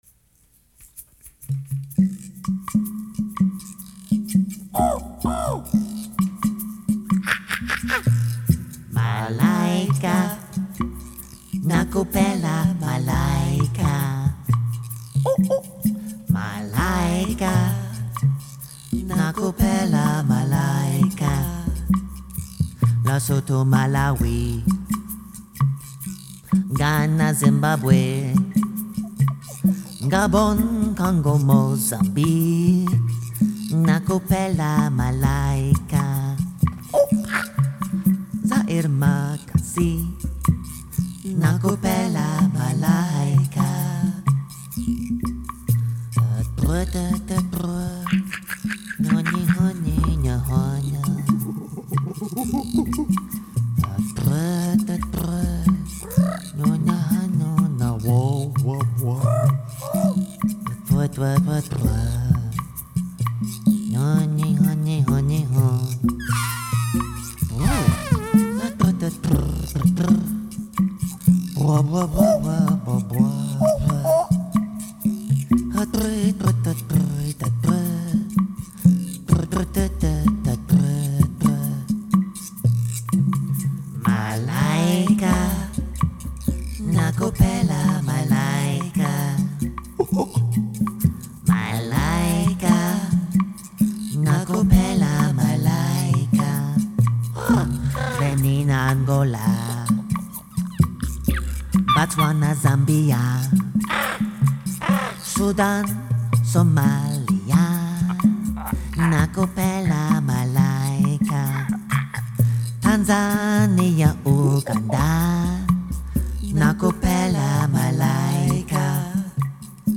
It sounds like awful taco bell jungle music.